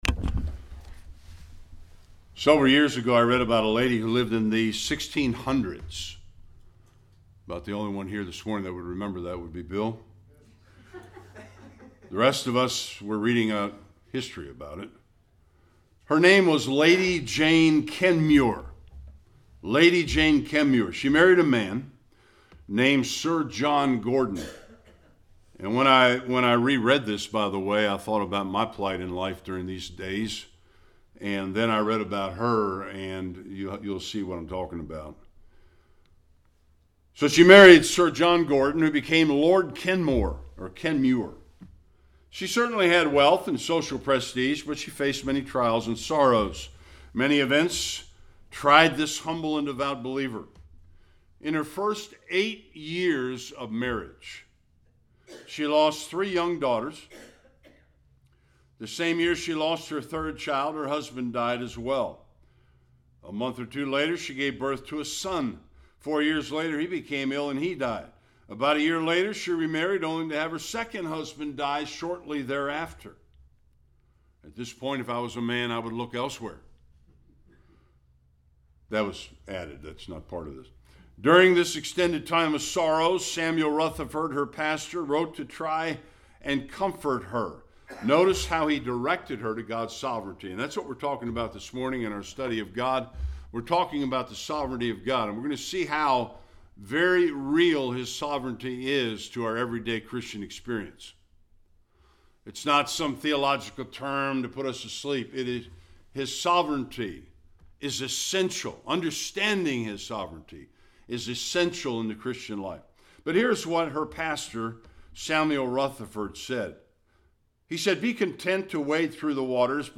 Various passages Service Type: Sunday Worship God’s sovereignty is very important as it relates to our everyday lives.